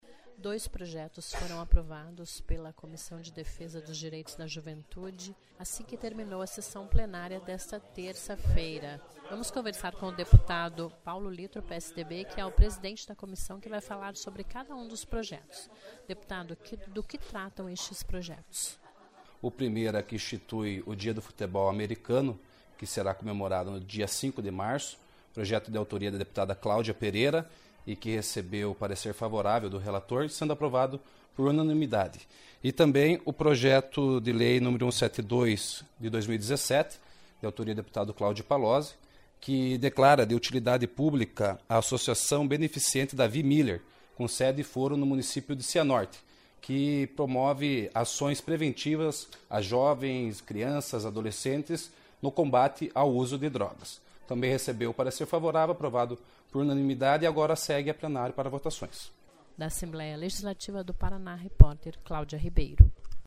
Ouça e íntegra da entrevista com deputado Paulo LItro (PSDB), presidente da Comissão.